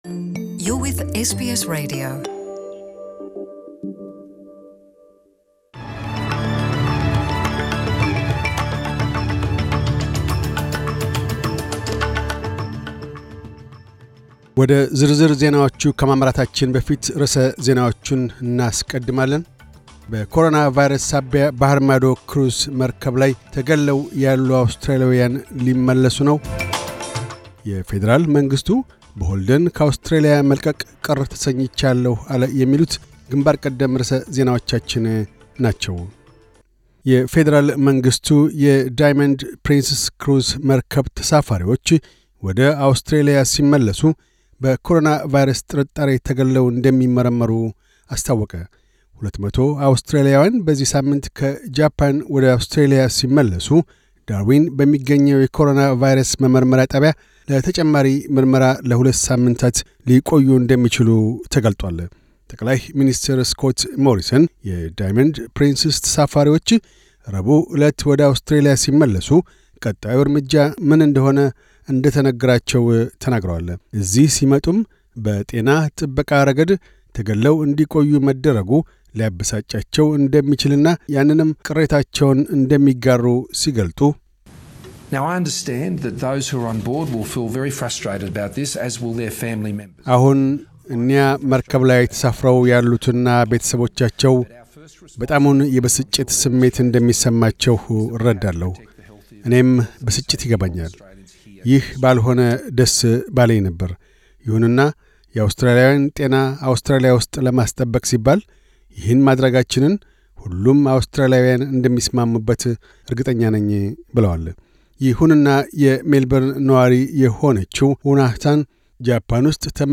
News Bulletin 1702